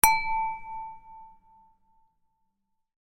Sound Effect
A sound effect for in-game actions.
clink.mp3